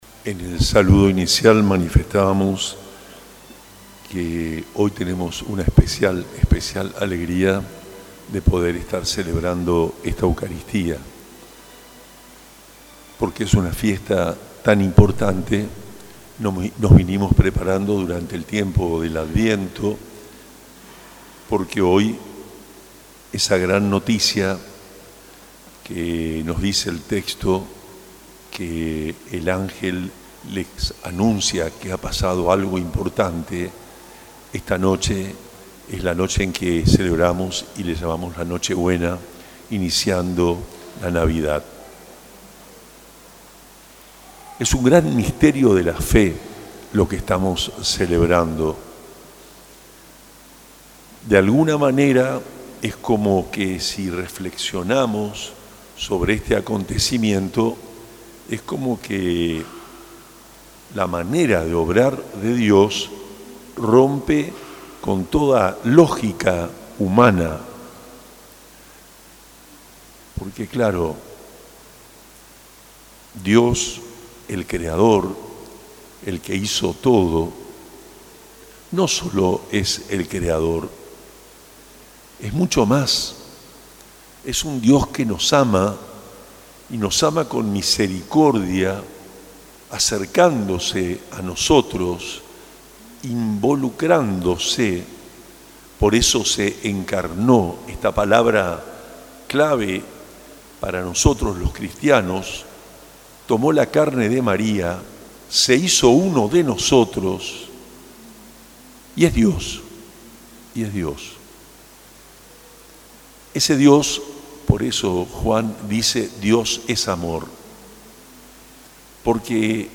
En la noche del 24 de diciembre, durante la Misa de Nochebuena celebrada en la Iglesia Catedral San José de Posadas, monseñor Juan Rubén Martínez, obispo de la Diócesis de Posadas, ofreció una profunda reflexión sobre el misterio de la Navidad y su significado para la fe cristiana.
HOMILIA-ABISPO-NOCHE-BUENA.mp3